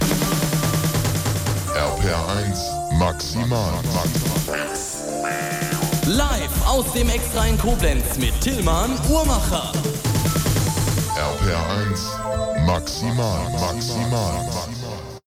Live aus dem Extra in Koblenz mit